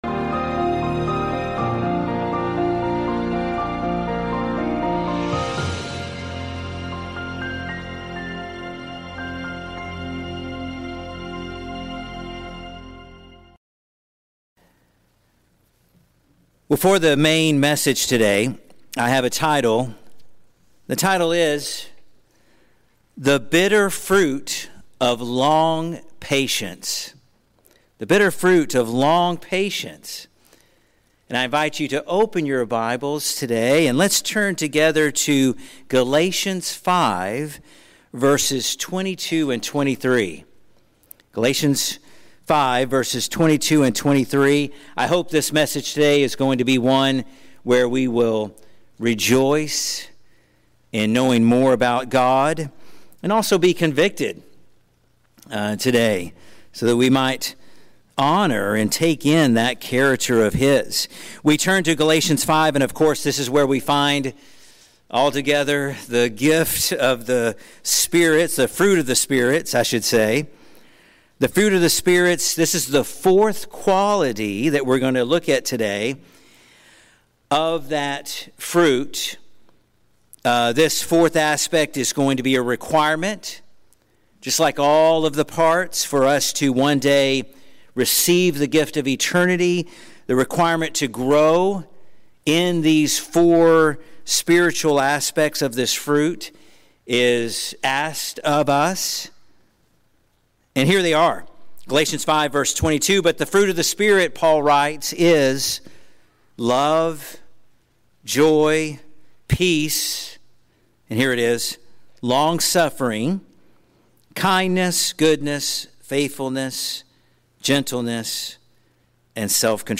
In this sermon on the “bitter but beautiful” fruit of longsuffering from Galatians 5:22–23, we are reminded that true long patience—makrothyméō—is not merely enduring inconvenience, but bravely bearing offenses with grace, just as God has done toward us. Through powerful glimpses of God’s character in Book of Exodus 34, His mercy toward Nineveh in Book of Jonah, and the perfect restraint of Christ in the Gospel of Matthew, we see that our salvation itself rests on divine patience.